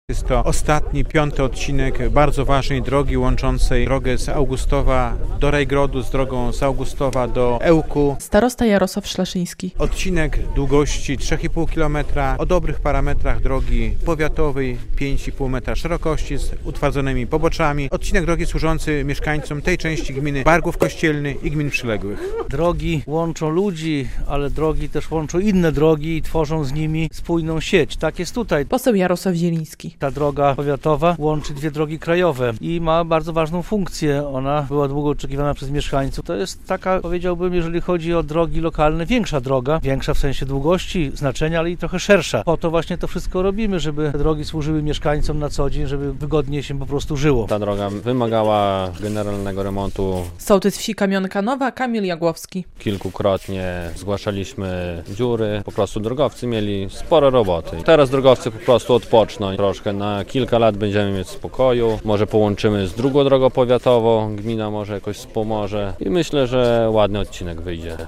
- Jest to ostatni odcinek drogi, który łączy drogę z Augustowa do Rajgrodu z trasą z Augustowa do Ełku. Ma on długość ok. 3,5 kilometra i szerokość 5 metrów. Zostały tu utwardzone pobocza. Korzystają z niej mieszkańcy tej części gminy Bargłów Kościelny oraz przyległe samorządy - mówił starosta augustowski Jarosław Szlaszyński.